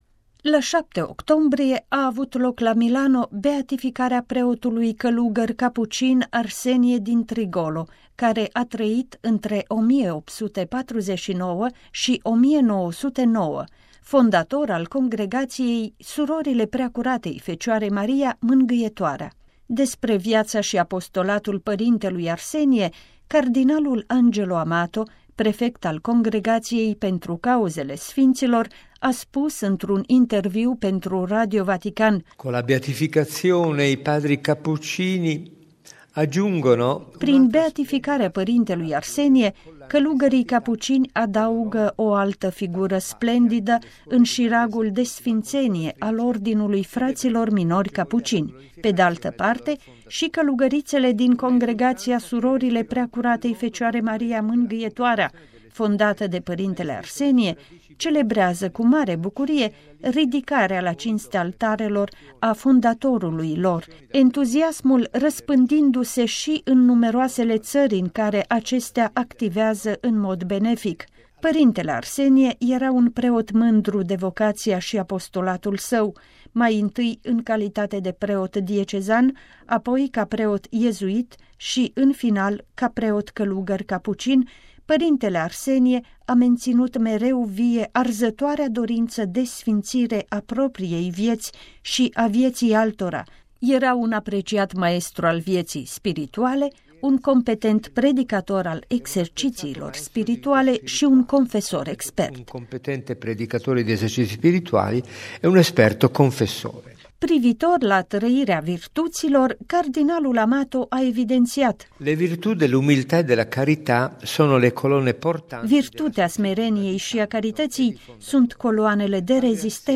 Despre viața și apostolatul părintelui Arsenie, cardinalul Angelo Amato, prefectul Congregației pentru Cauzele Sfinților, a spus într-un interviu pentru Radio Vatican: